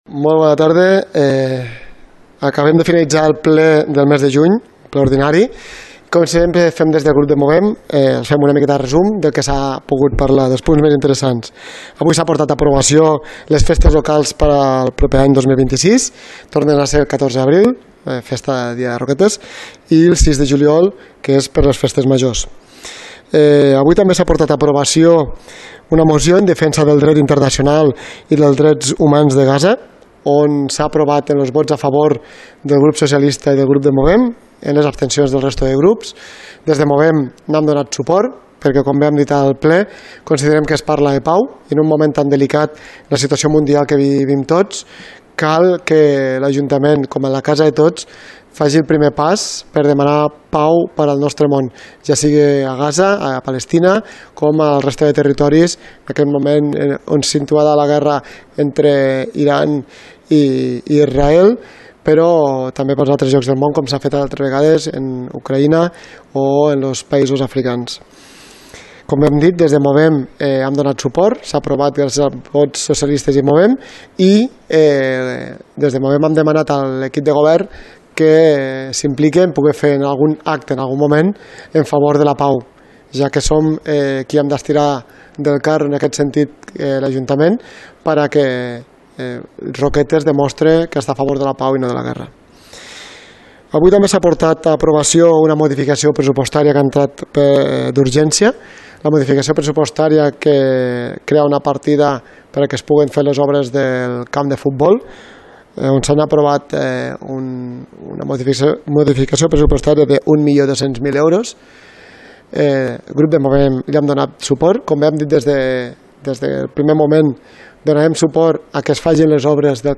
Ple Ordinari de Roquetes – Juny 2025 – Declaracions – Movem Roquetes – David Poy | Antena Caro - Roquetes comunicació